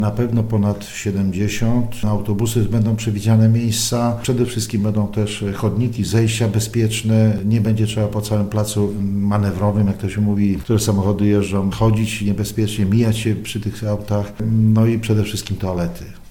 Czesław Kalbarczyk, wójt gminy Łagów, mówi, że rewitalizacja podzamcza, budowa promenady i obiektu wystawienniczego została pozytywnie odebrana przez tegorocznych turystów: